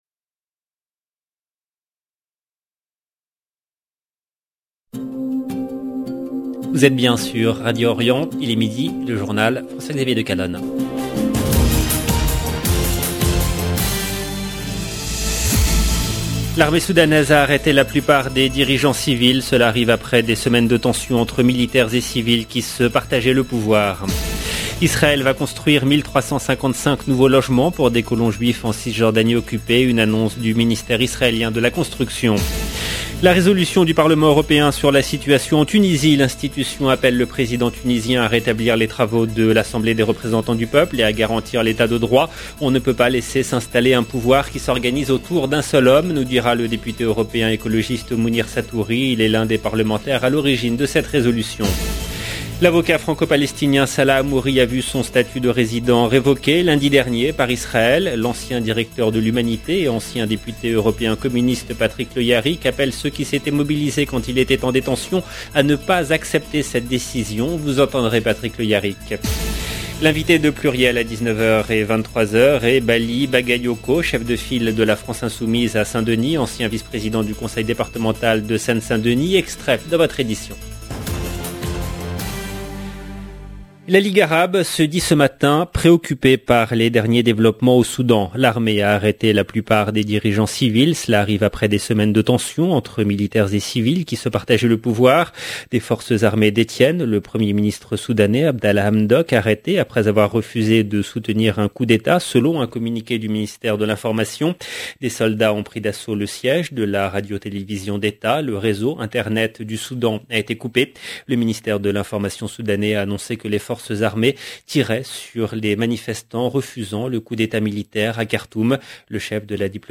JOURNAL DE 12 H EN LANGUE FRANCAISE
Vous entendrez Patrick Le Hyaric.